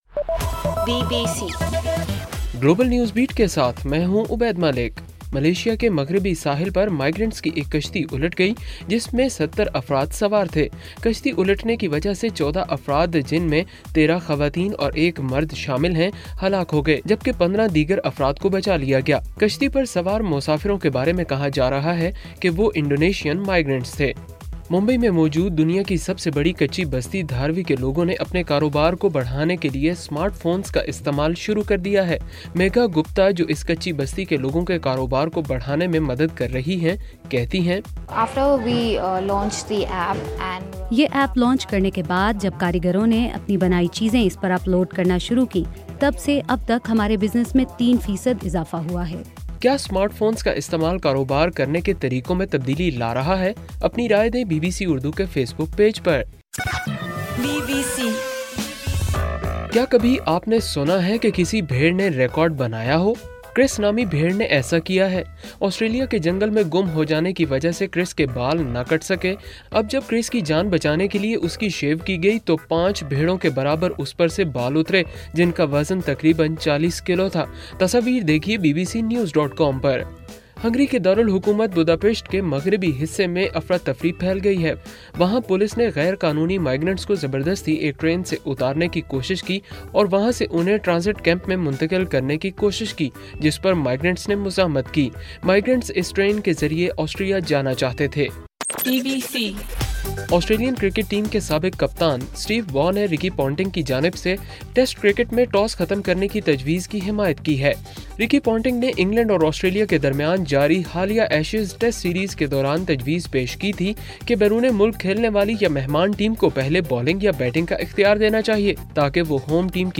ستمبر 3: رات 9 بجے کا گلوبل نیوز بیٹ بُلیٹن